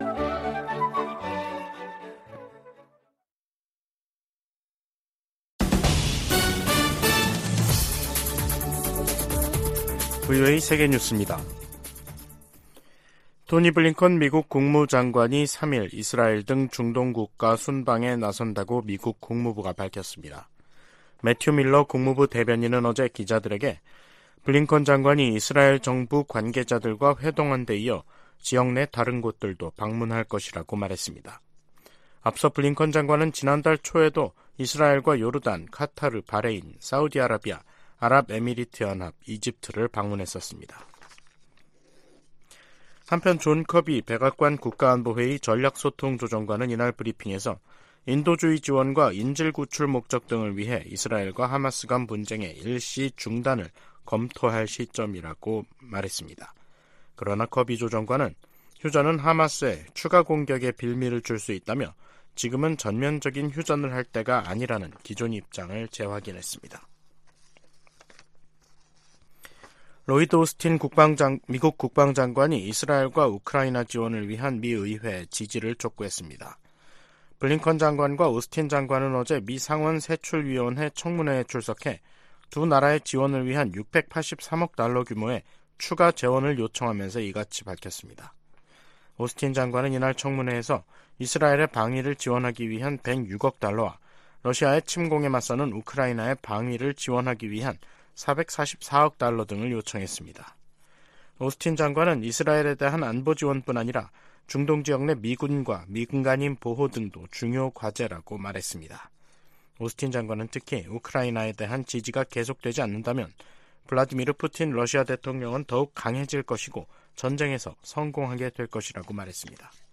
VOA 한국어 간판 뉴스 프로그램 '뉴스 투데이', 2023년 11월 1일 2부 방송입니다. 미 국방부는 한국 정부가 9.19 남북군사합의의 효력 정지를 검토 중이라고 밝힌 데 대해 북한 위협에 대응해 한국과 계속 협력할 것이라고 밝혔습니다. 한국 국가정보원은 북한이 러시아의 기술자문을 받으면서 3차 군사정찰위성 발사 막바지 준비를 하고 있다고 밝혔습니다. 미 국토안보부장관은 북한 등 적성국 위협이 진화하고 있다고 말했습니다.